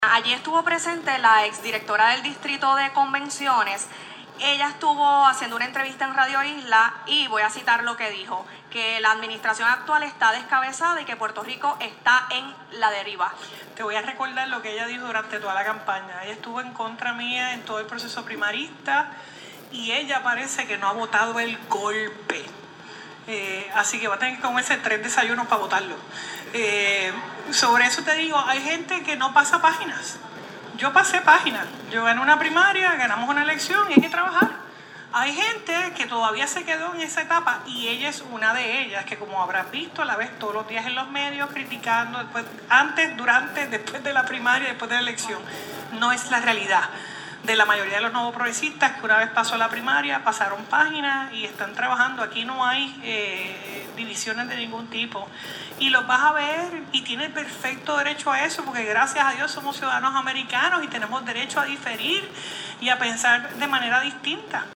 Por su parte, la gobernadora reaccionó durante la conferencia de prensa hoy, lunes a los comentarios de la exdirectora ejecutiva en Radio Isla 1320 AM.